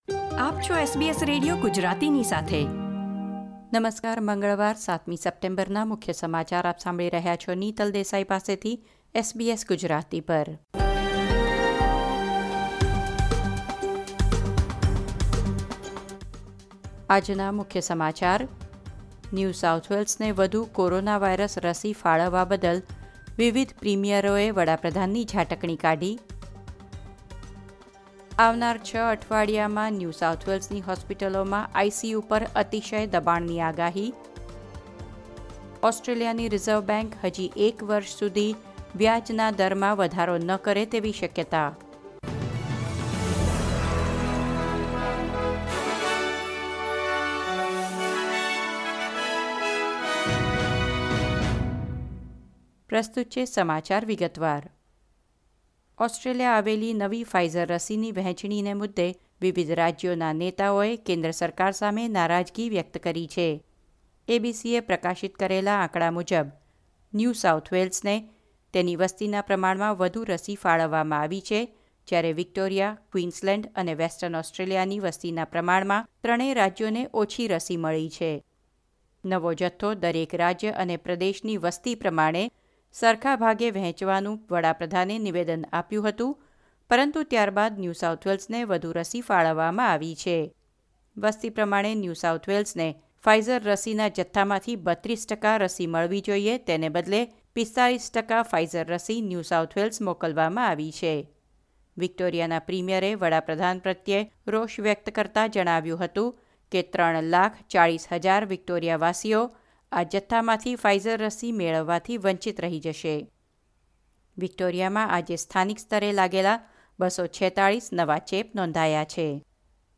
SBS Gujarati News Bulletin 7 September 2021